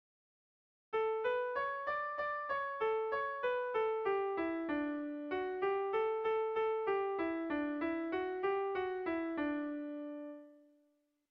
Air de bertsos - Voir fiche   Pour savoir plus sur cette section
Gabonetakoa
Legorreta < Goierri < Gipuzkoa < Euskal Herria
Lauko txikia (hg) / Bi puntuko txikia (ip)
AB